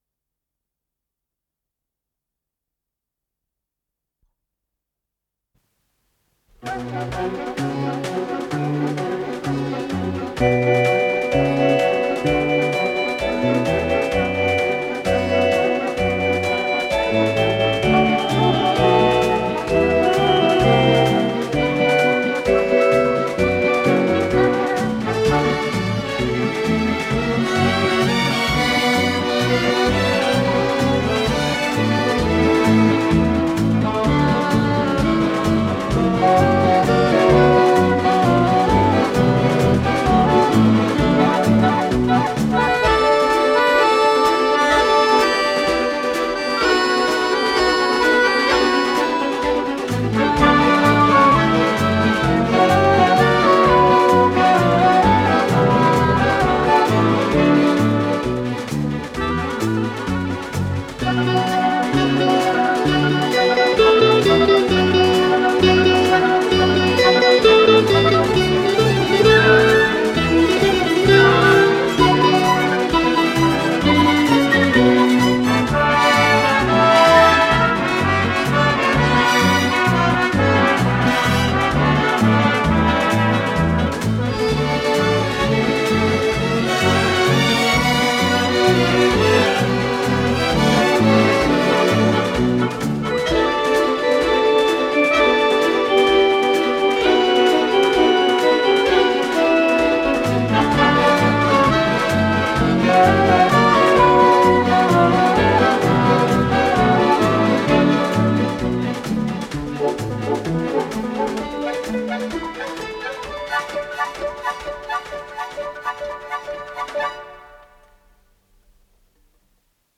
с профессиональной магнитной ленты
си минор - ми минор
ВариантДубль моно